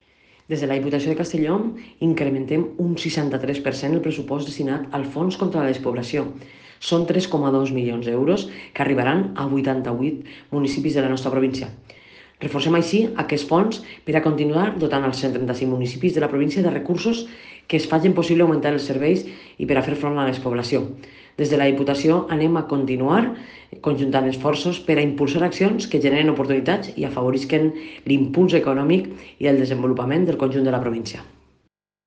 Presidenta-Marta-Barrachina-Fondo-Despoblacion.mp3